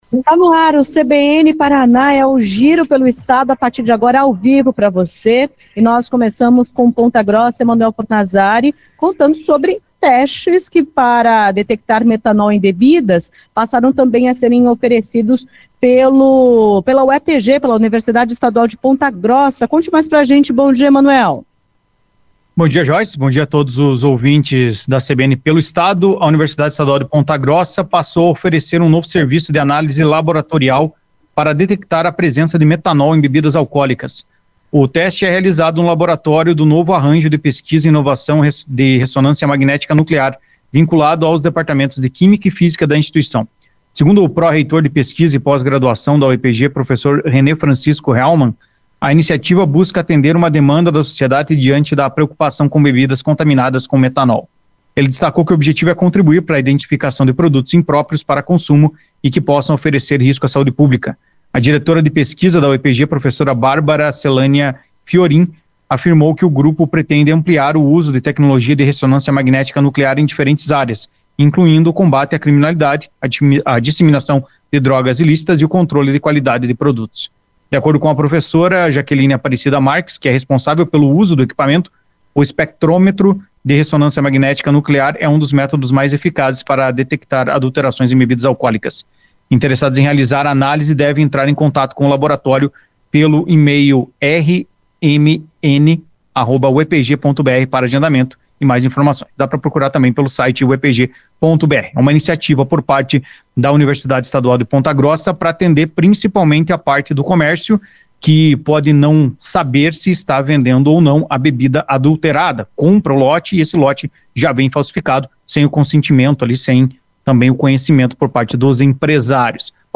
O CBN Paraná vai ao ar de segunda a sexta-feira, a partir das 10h35, com participação da CBN Maringá, CBN Curitiba, CBN Londrina, CBN Cascavel e CBN Ponta Grossa.